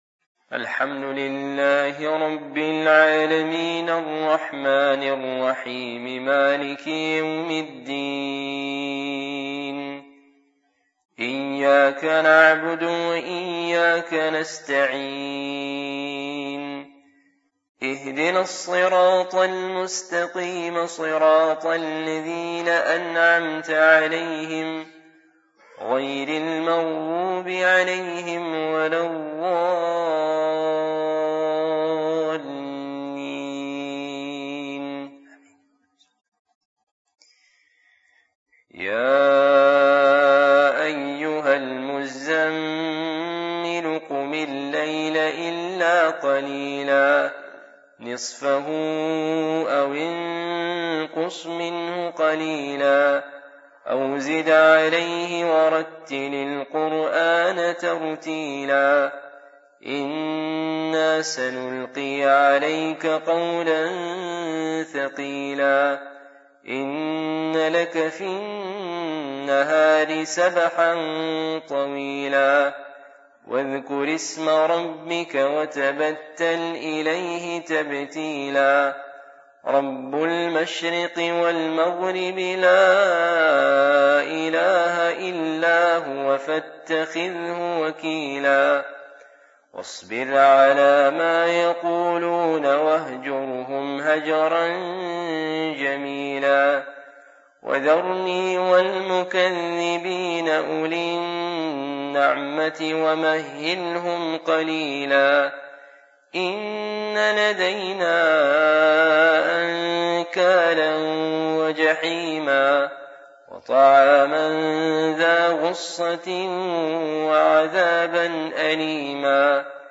Fajr, Qiraat And Dua